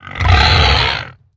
Minecraft Version Minecraft Version snapshot Latest Release | Latest Snapshot snapshot / assets / minecraft / sounds / mob / hoglin / retreat1.ogg Compare With Compare With Latest Release | Latest Snapshot